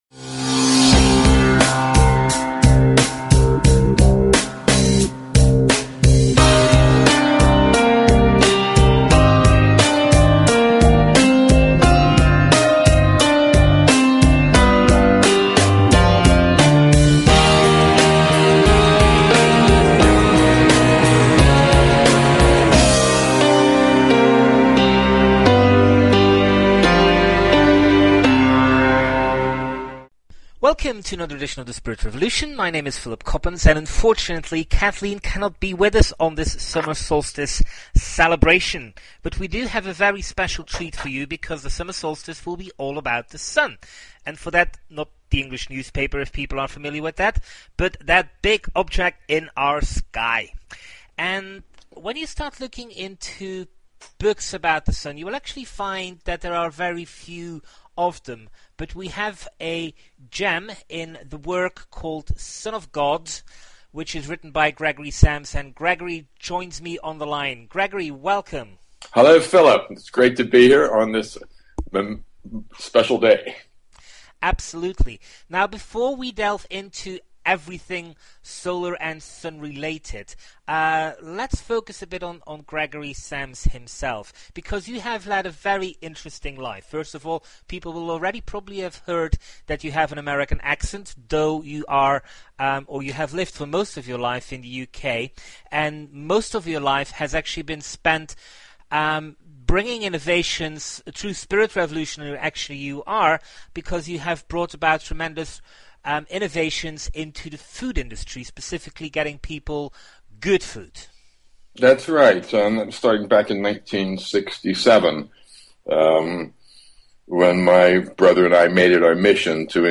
Talk Show Episode, Audio Podcast, The_Spirit_Revolution and Courtesy of BBS Radio on , show guests , about , categorized as
The Spirit Revolution is a weekly one hour radio show, in which Kathleen McGowan and Philip Coppens serve up a riveting cocktail of news, opinion and interviews with leaders in the fields of alternative science, revisionist history and transformational self-help.